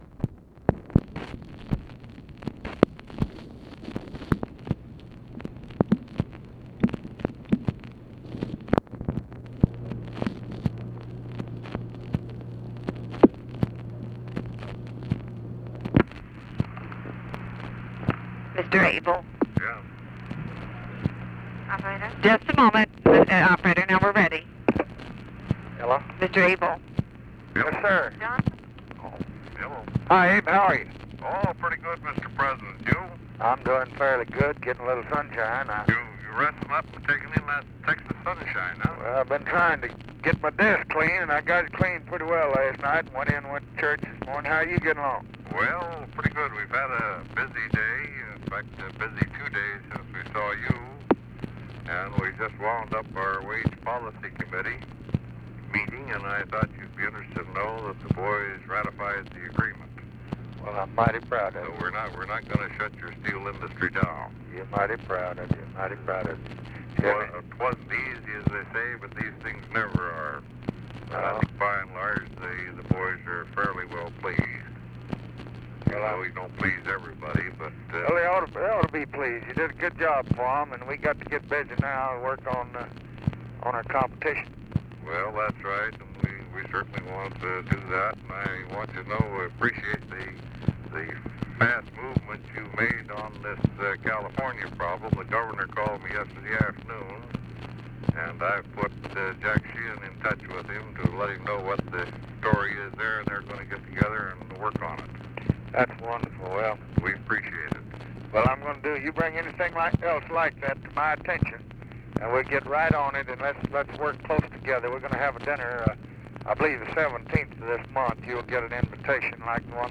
Conversation with I.W. ABEL, September 5, 1965
Secret White House Tapes